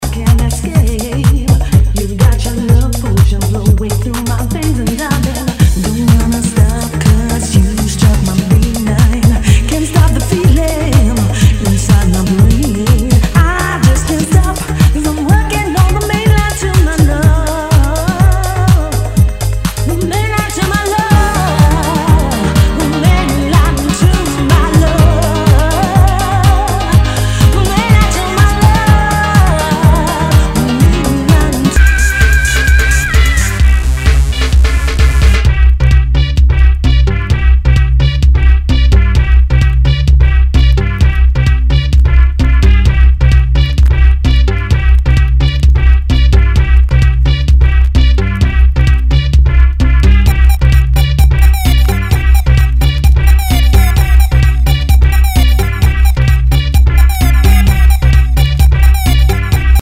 HOUSE/TECHNO/ELECTRO
ナイス！ヴォーカル・ハウス / テクノ・クラシック！